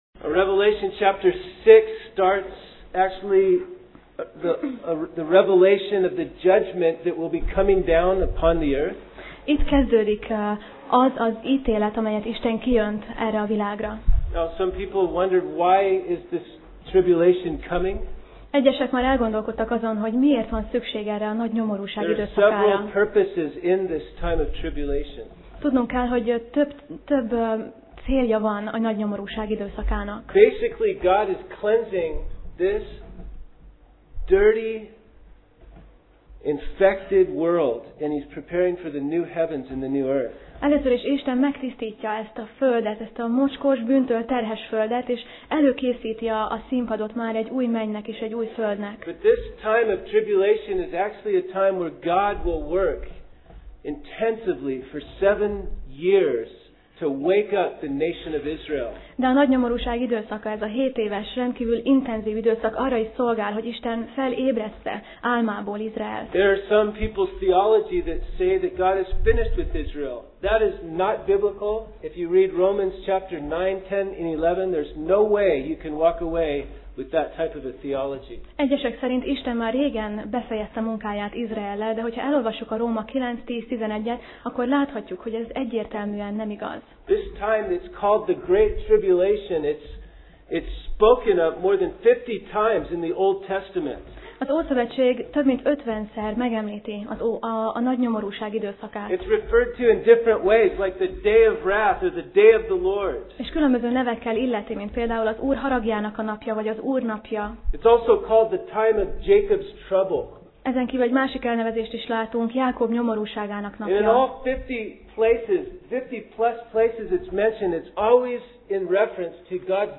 Sorozat: Jelenések Passage: Jelenések (Revelation) 6 Alkalom: Vasárnap Reggel